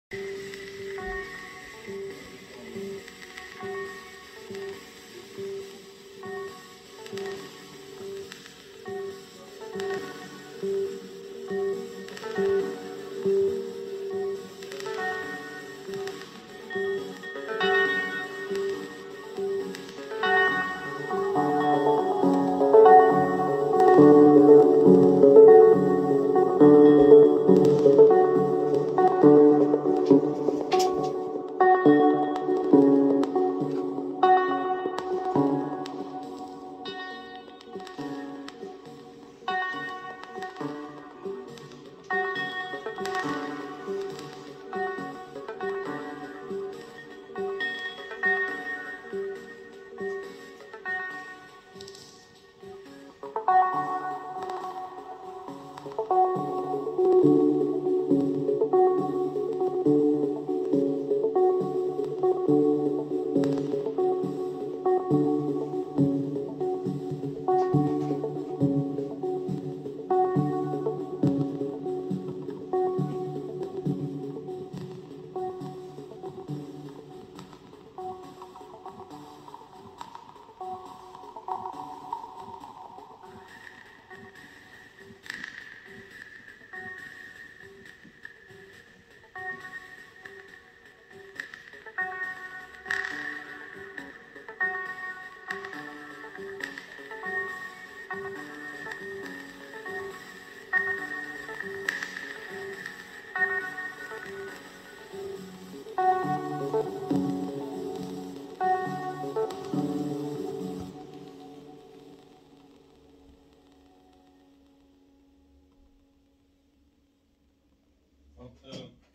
longer recording Model:Samples, landola guitar samples , 6 open strings